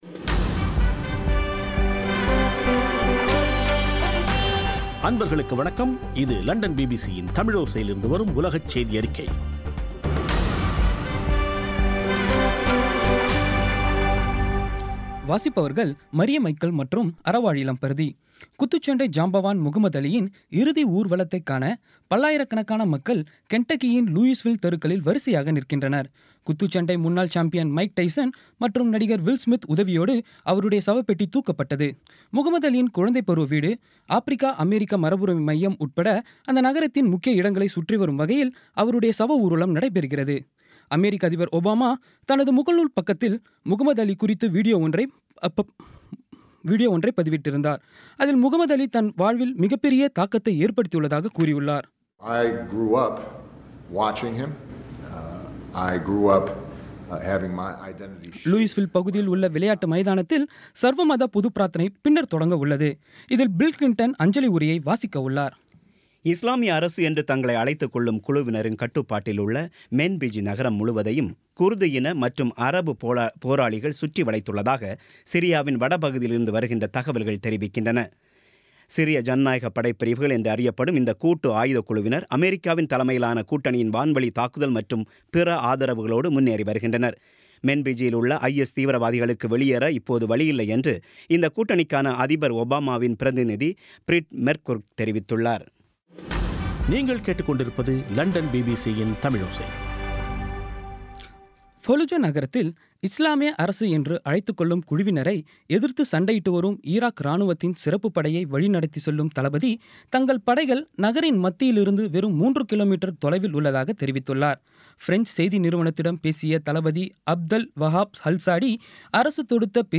இன்றைய (ஜூன் 10ம் தேதி ) பிபிசி தமிழோசை செய்தியறிக்கை